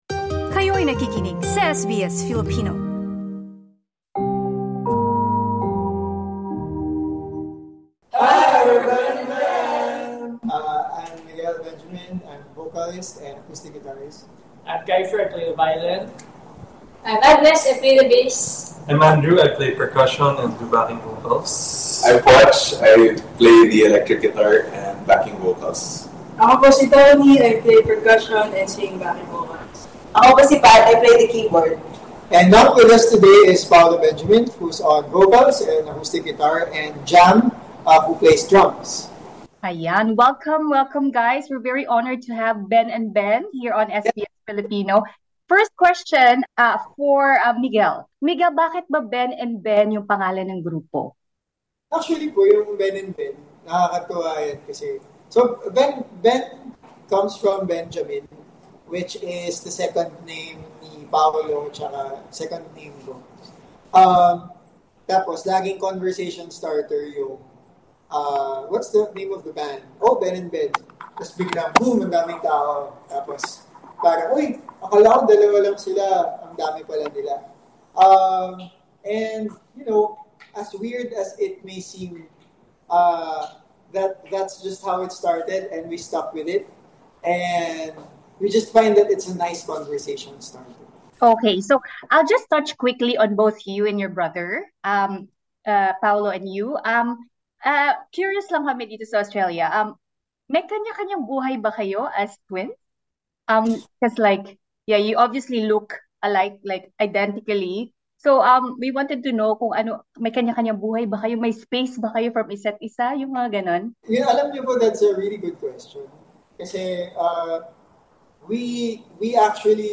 Prior to their journey to Australia, the nine-member band sat down with SBS Filipino to discuss their upcoming show in Sydney and provide insight into their distinctive creative music-making process.